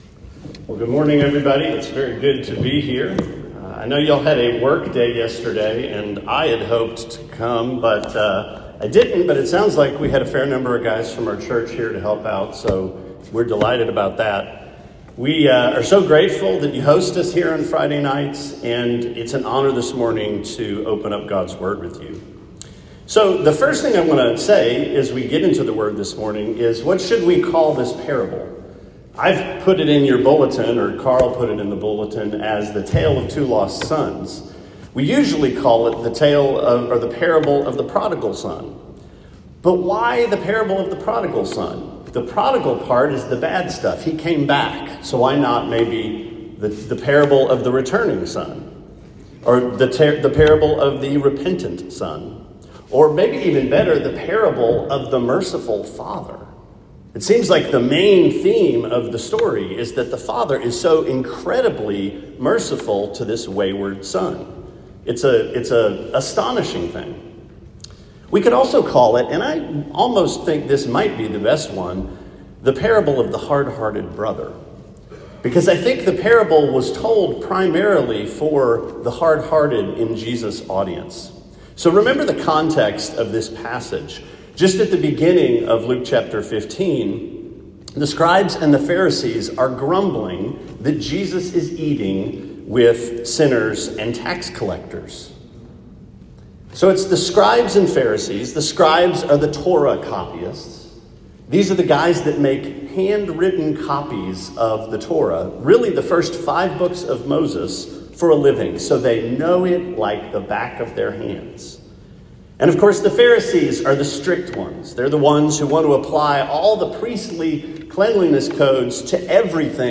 Anchor Baptist Sermon 6/1: Tale of the Two Lost Sons